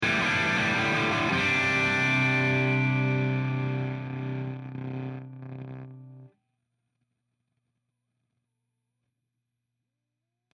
I also discovered another issue, i’m getting pops and clicks when using the high gain channel of my amp while having either Brite + Warm engaged, or Edge + Deep engaged. These sounds don’t happed on the Flat settings, or when i’m on the clean channel of my amp, so I’m assuming it is some how related to the brite warm modes etc. Would this be a tube issue, or something else?